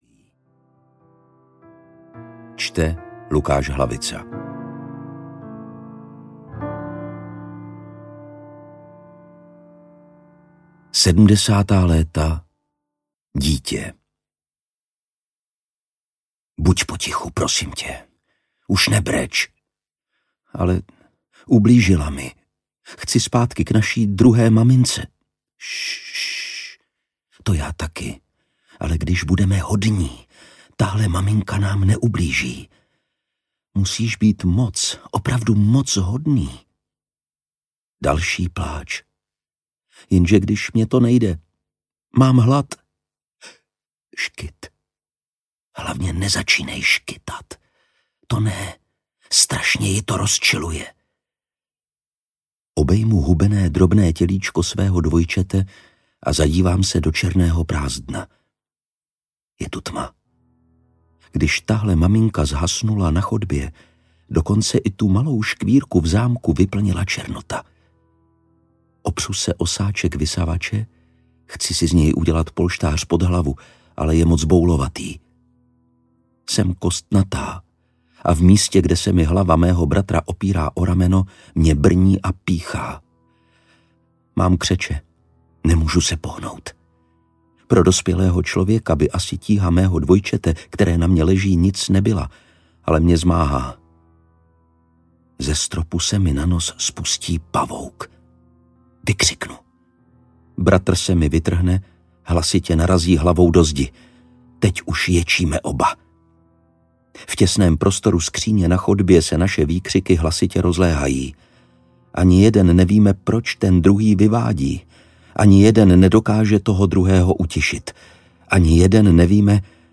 Stará tajemství audiokniha
Ukázka z knihy
stara-tajemstvi-audiokniha